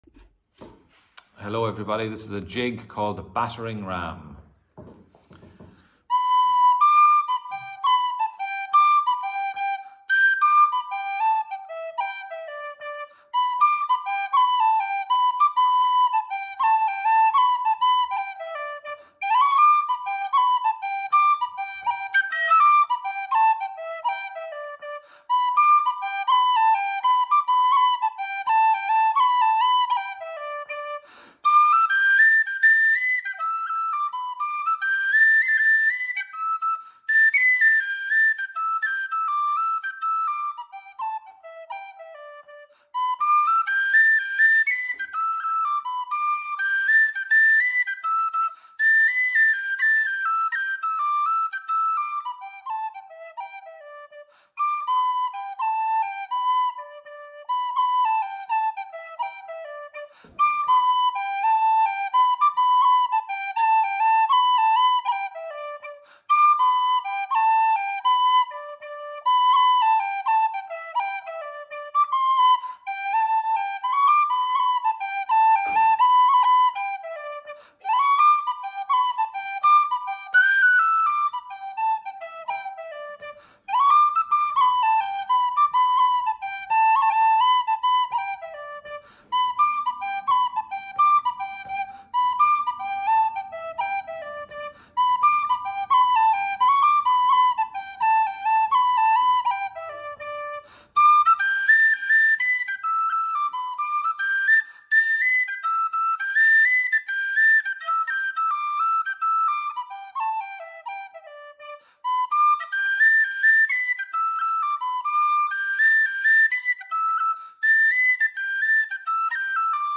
Je n’ai pas ralenti les enregistrements car la vitesse me parait déjà bien pour apprendre.
tin whistle, partition et abc.
The-Battering-Ram-Jig-tin-whsitle.mp3